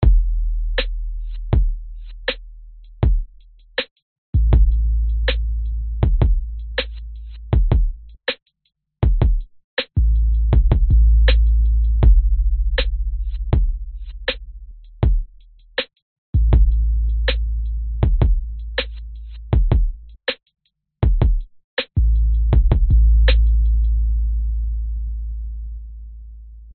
嘻哈鼓1
描述：9条嘻哈鼓循环，80bpm
Tag: 嘻哈 循环播放